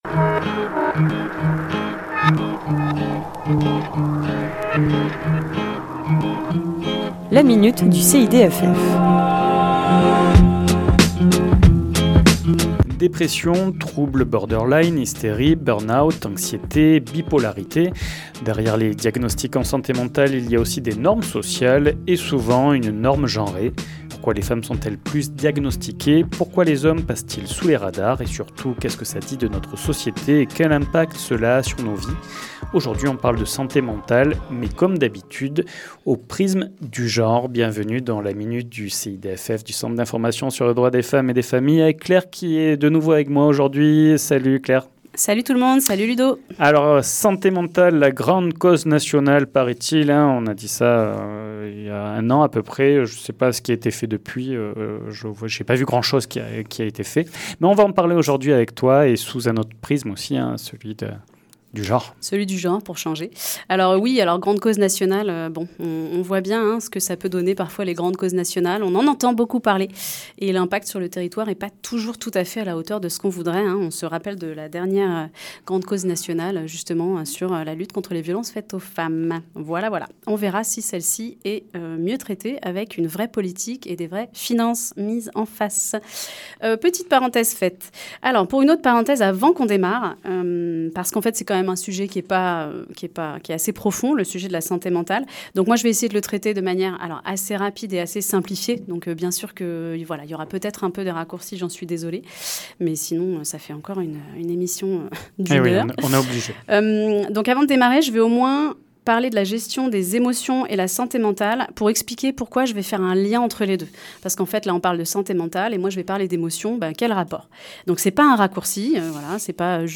Chronique diffusée le lundi 15 septembre à 11h00 et 17h10